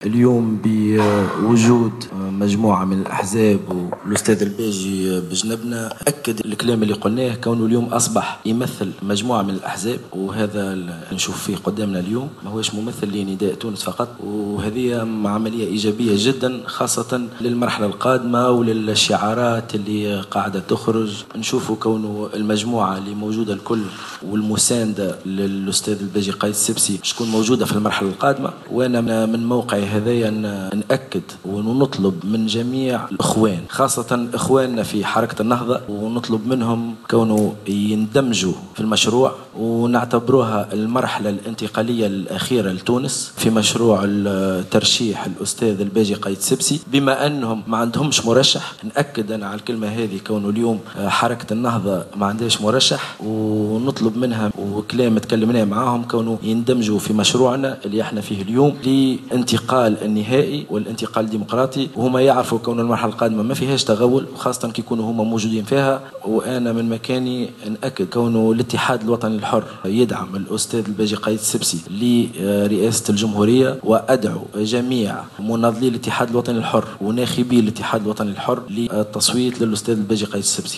Il a appelé, également, lors d’une conférence de presse tenue ce jour même, tous ces partisans à voter pour le candidat de Nidaa Tounes.